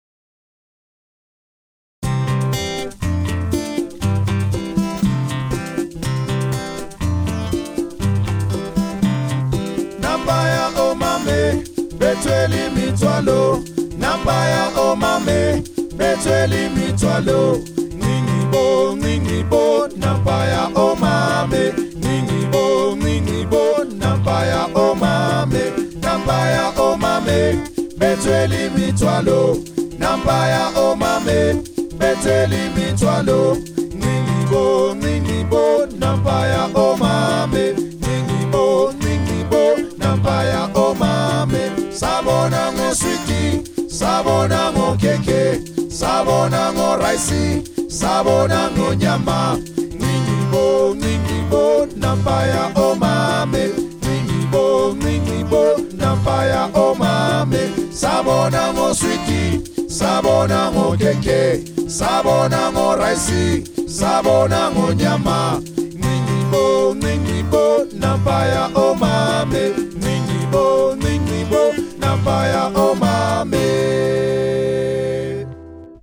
Style :  Avec accompagnement
Effectif :  PolyphonieUnissonVoix mixtes
Enregistrement tutti